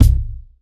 Medicated Kick 3.wav